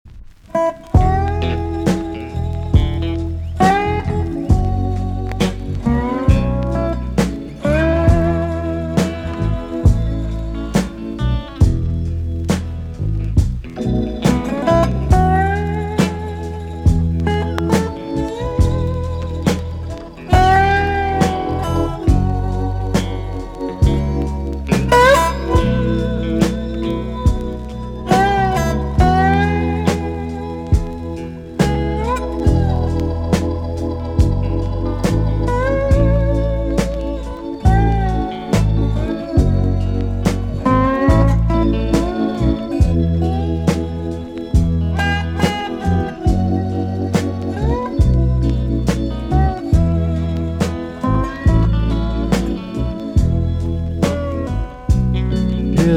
EX-音はキレイです。